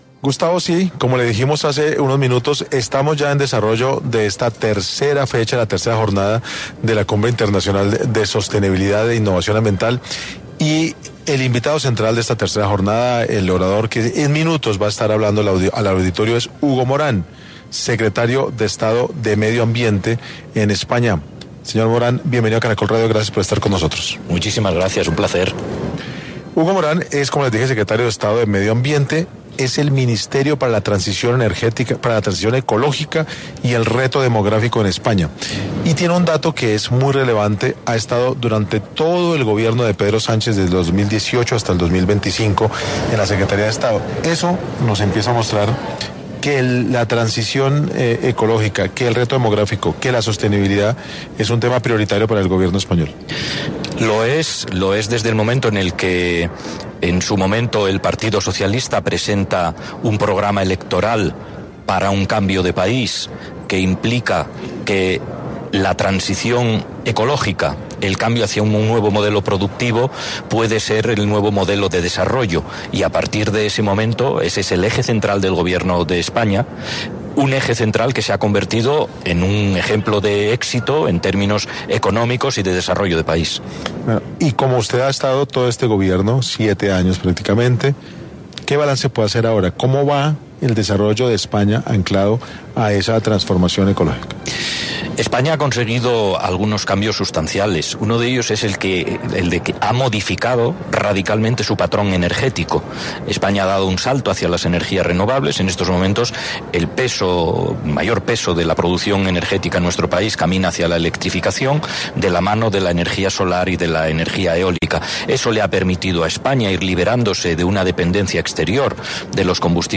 El secretario de Estado de Medio Ambiente de España habló en 6AM sobre la relación ambiental que hay con Colombia como modelo para otros países
En medio de la tercera jornada de la Cumbre Internacional de Sostenibilidad en el Centro de Eventos Ágora en Bogotá, un espacio promovido por la Corporación Autónoma de Cundinamarca, la Cámara de Comercio de Bogotá y Prisa Media, se habló con Hugo Morán, secretario de Estado de Medio Ambiente, de España sobre la relación ambiental con Colombia.